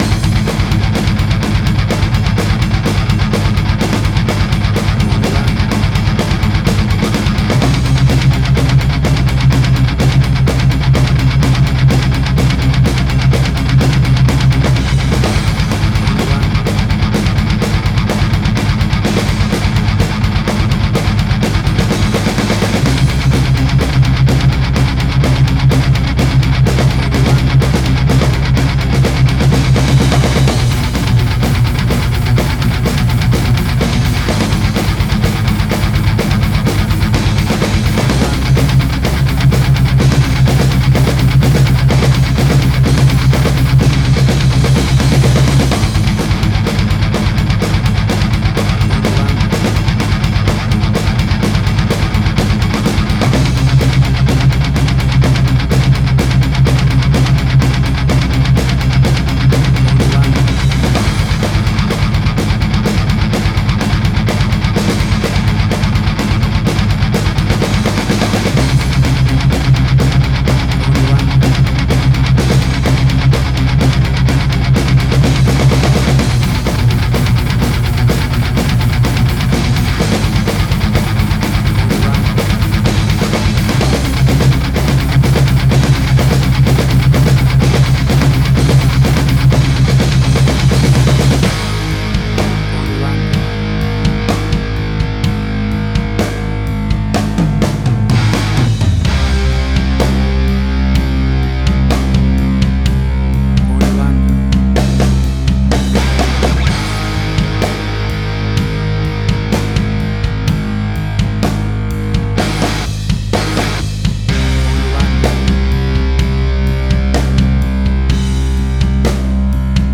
Hard Rock 101Alts.jpg
Hard Rock, Similar Black Sabbath, AC-DC, Heavy Metal.
Tempo (BPM): 125